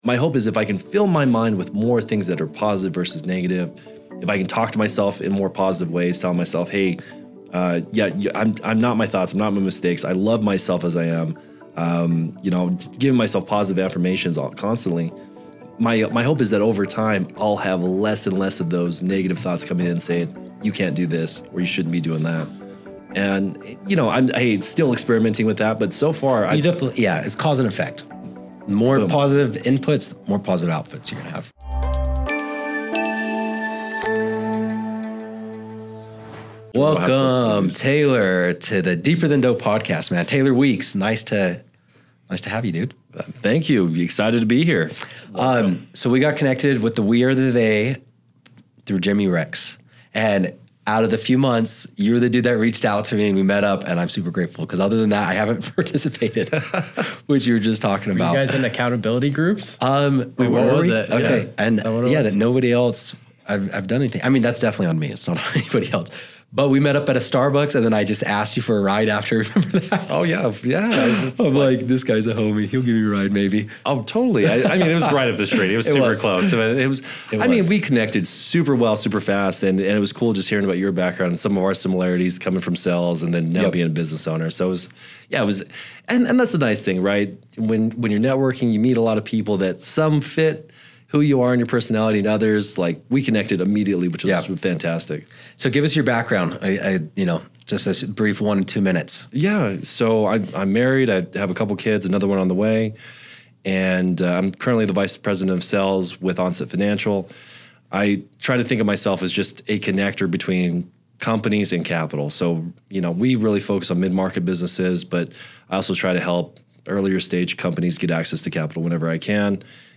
Expect insights that go beyond the surface, peppered with laughter and relatable anecdotes.